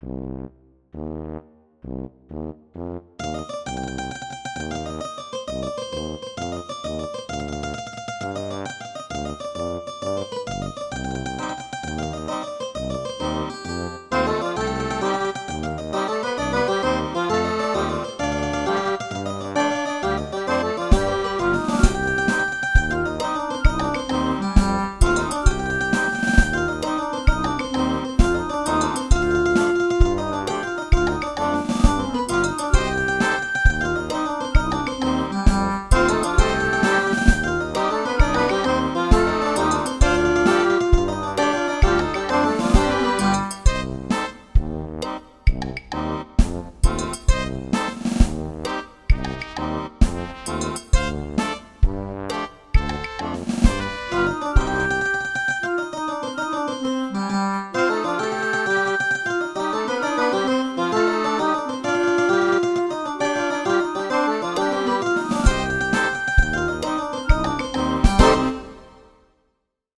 traccia di accompagnamento